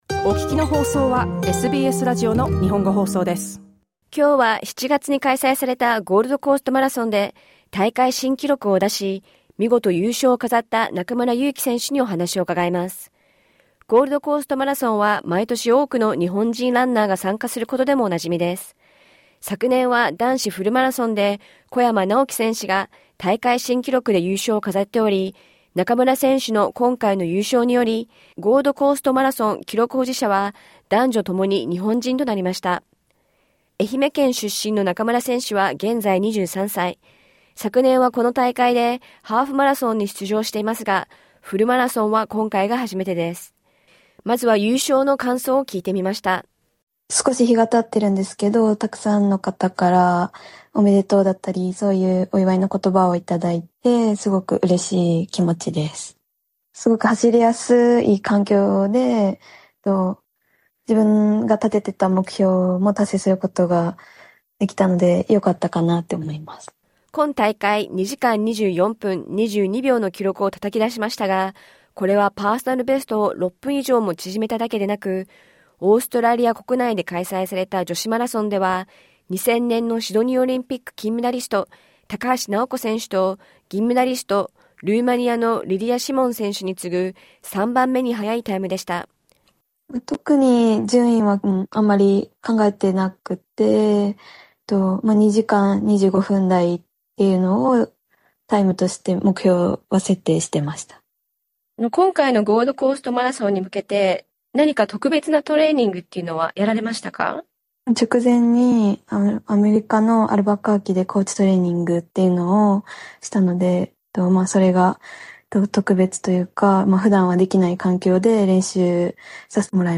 フルインタビューは音声から。